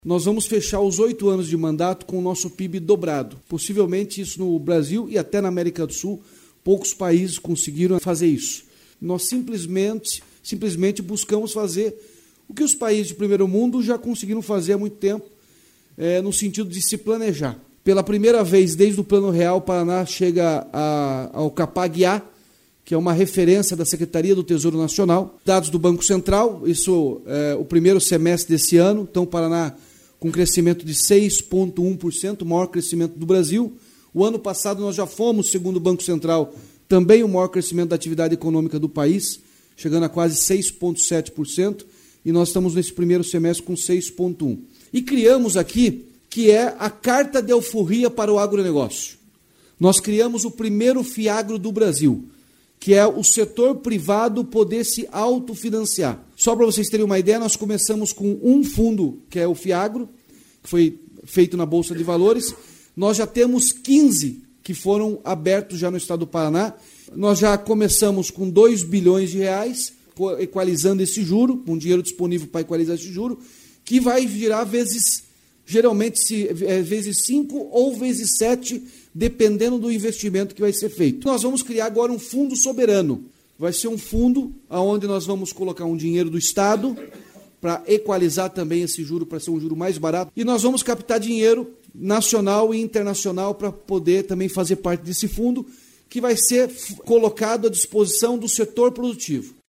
Sonora do governador Ratinho Junior na Associação Comercial de São Paulo